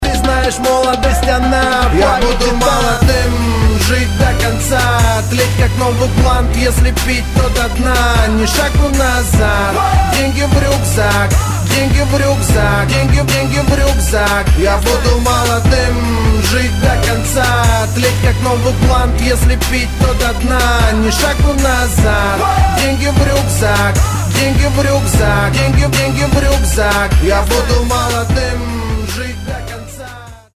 • Качество: 128, Stereo
пацанские